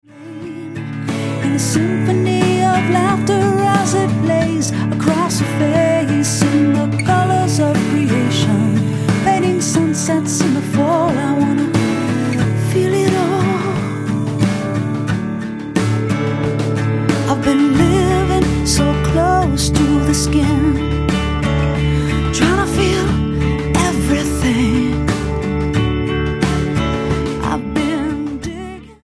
STYLE: Roots/Acoustic